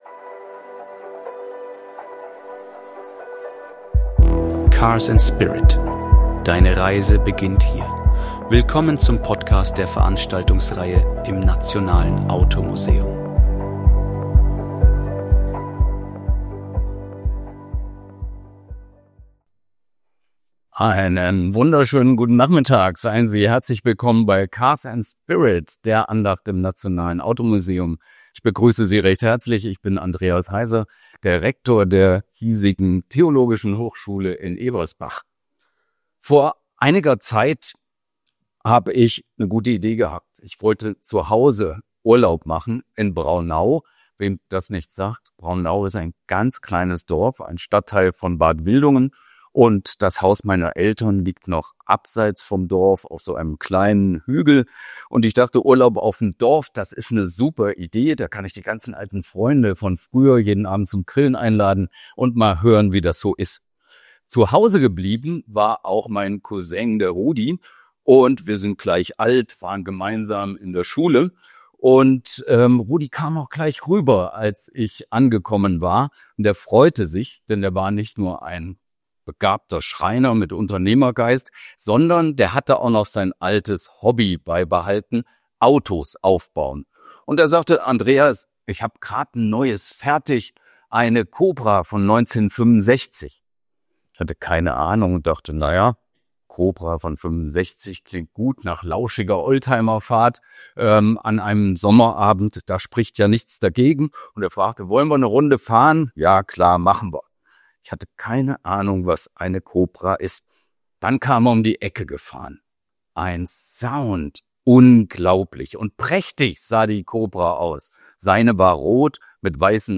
Beschreibung vor 5 Monaten Erleben Sie einen einzigartigen Vortrag im Nationalen Automuseum, der eindrucksvoll die Parallelen zwischen Autosport und Lebensvertrauen aufzeigt.